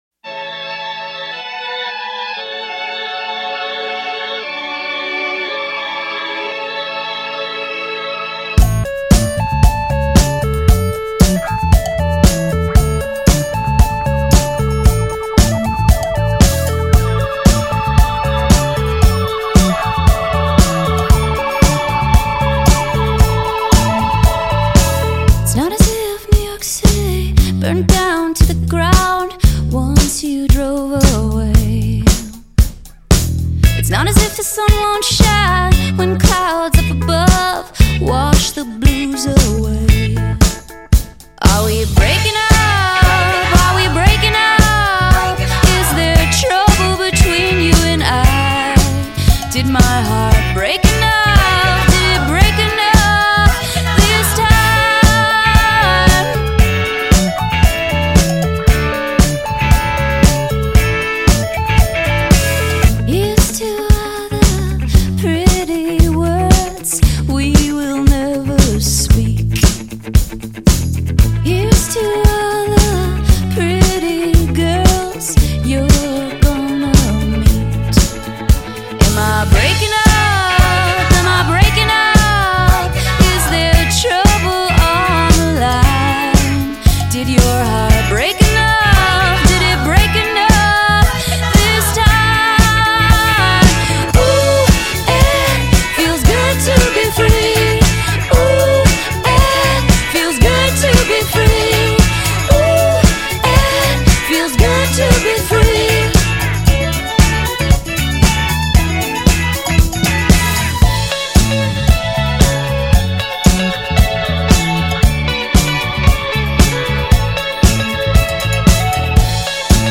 cowbell